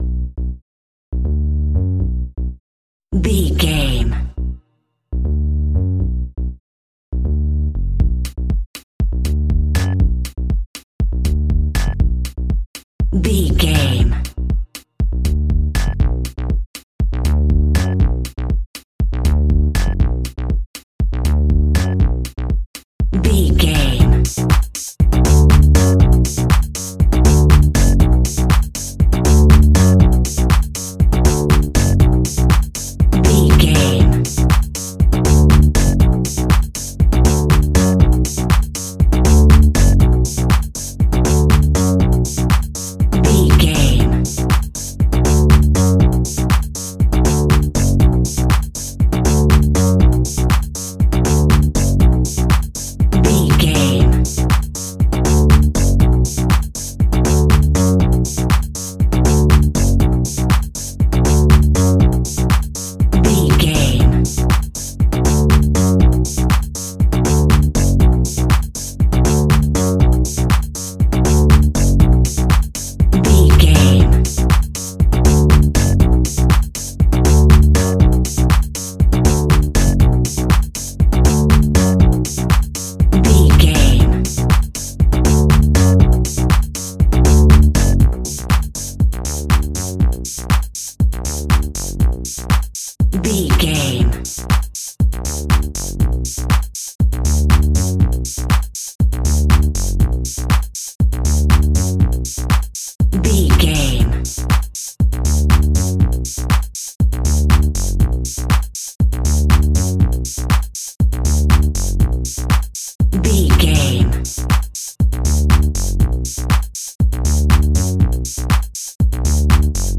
Aeolian/Minor
groovy
futuristic
uplifting
drums
bass guitar
funky house
disco house
electronic funk
energetic
upbeat
synth leads
Synth Pads
synth bass
drum machines